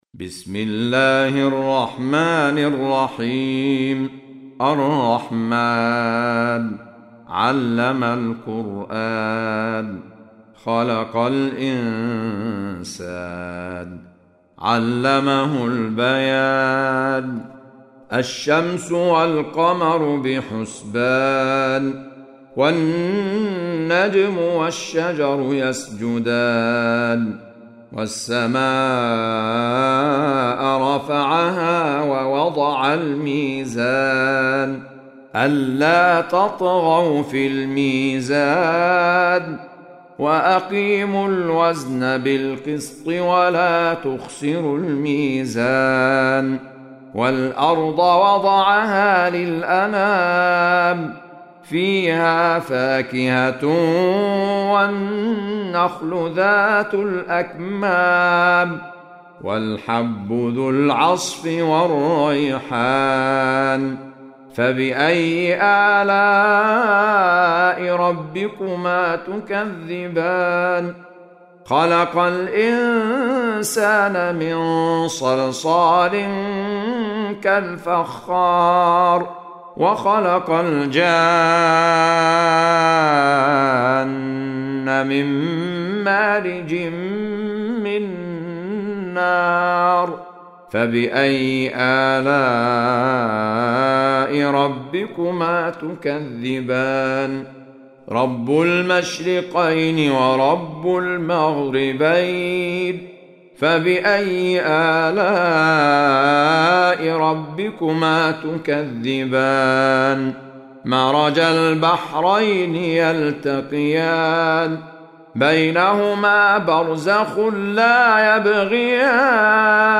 سورة الرحمن | القارئ أحمد عيسي المعصراوي